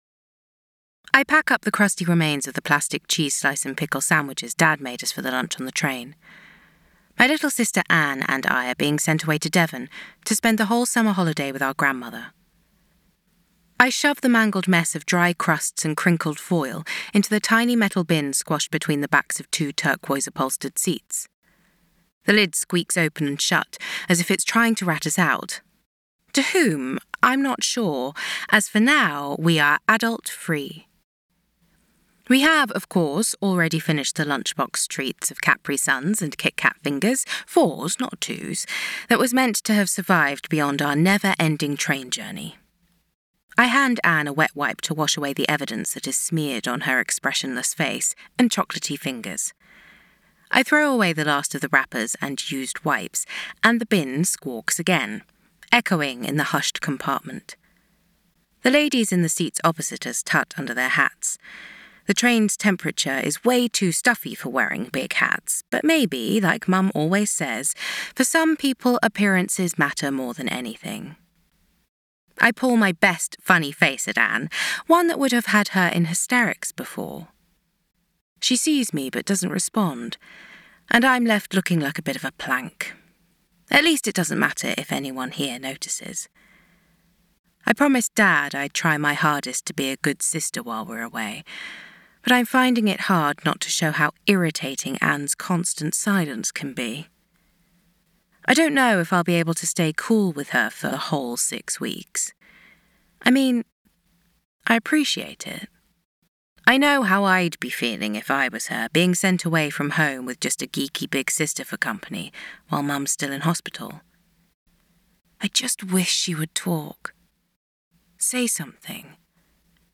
To Autumn by Katie M. Hall [Audiobook]